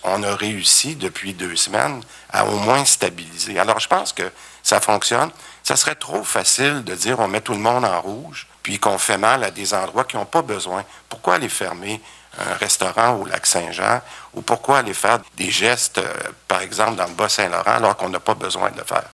Dans son point de presse  mardi, le ministre de la Santé, Christian Dubé, a finalement écarté la possibilité de faire passer tout le Québec en zone d’alerte maximale. Il a cependant appelé à la maintenance de la vigilance: